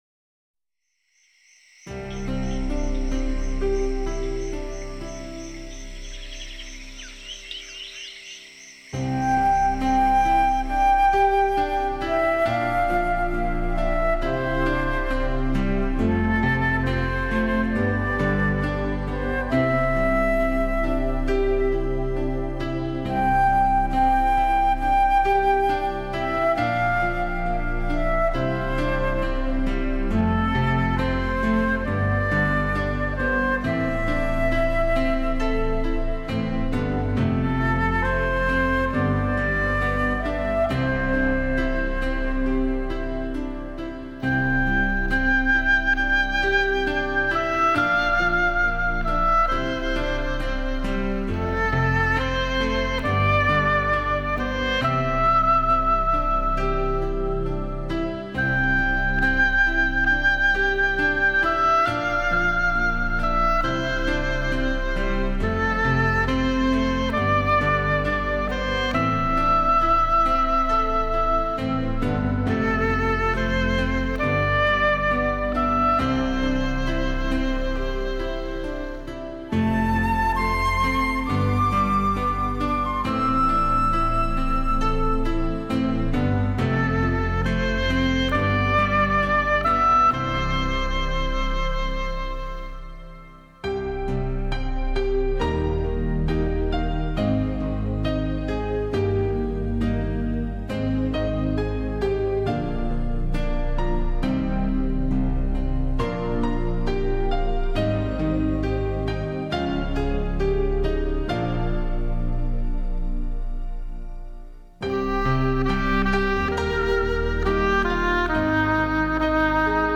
新世纪
细腻的钢琴，配上优美的横笛，是用来诠释春天最好的组合。